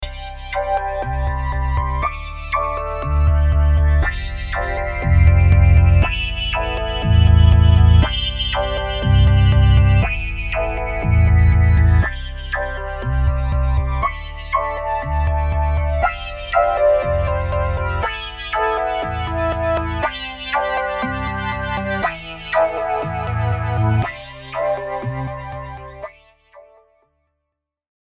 I haven’t even tried any of the presets that it came with yet, just starting from a blank preset and going from there, and I’ve already created some interesting stuff, like the following clip, which is just one of my synth programs applied to a simple set of notes: